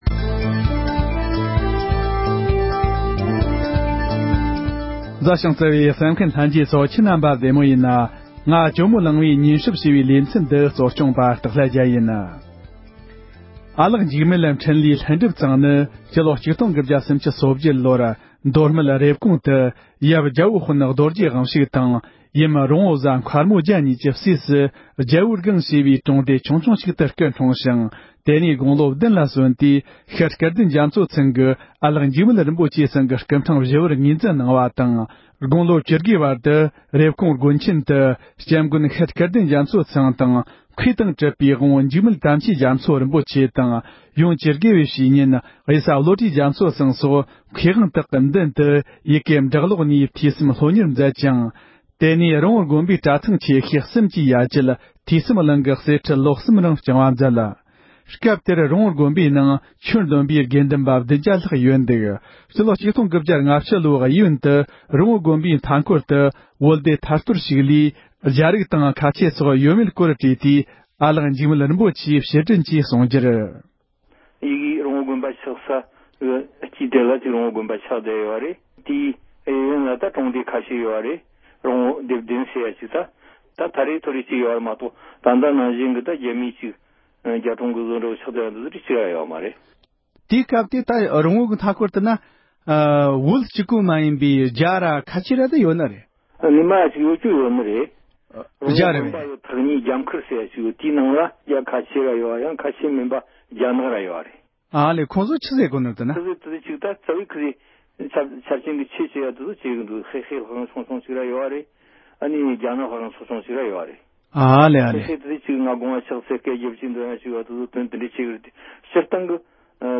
བཀའ་ཟུར་ཨ་ལགས་འཇིགས་མེད་རིན་པོ་ཆེ་མཆོག་དགོངས་པ་མ་རྫོགས་པའི་སྔོན་ལ་ཁོང་གི་སྐུ་ཚེ་ལོ་རྒྱུས་སྐོར་བཅར་འདྲི་ཞུས་པའི་དུམ་བུ་དང་པོ།